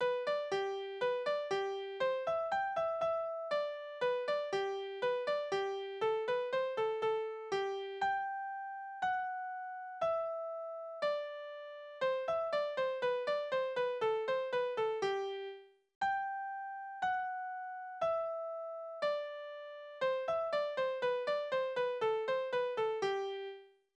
Lott iß dod Kinderlieder
Tonart: G-Dur Taktart: 4/4
Bauerntanz um 1860 in Hinterpommern